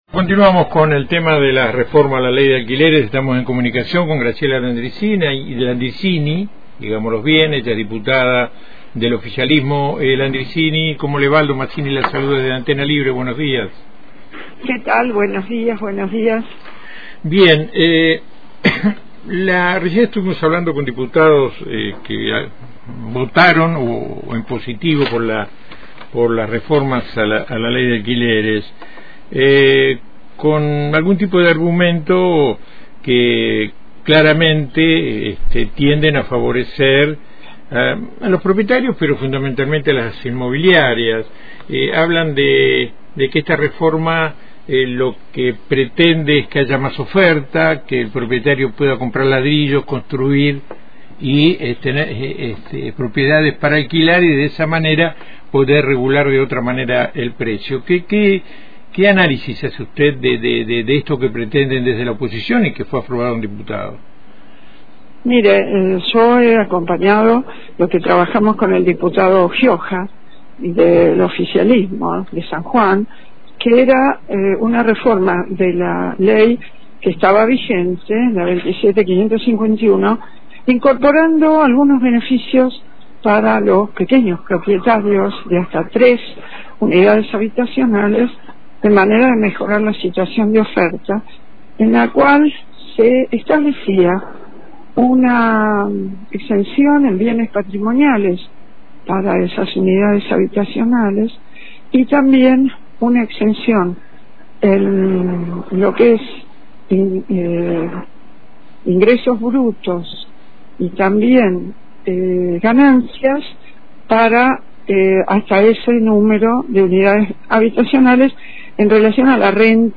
Podes escuchar la entrevista completa a Graciela Landriscini acá abajo: